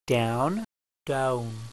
• the ow and oo blend (pronounced "owoo" as in owl)